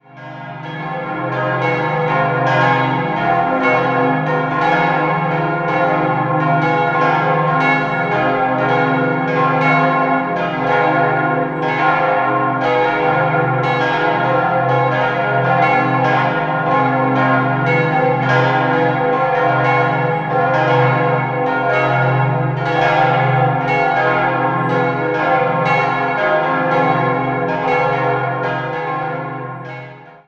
Idealquartett c'-es'-f'-as' Die Glocken wurden 1962 von Engelbert Gebhard in Kempten gegossen.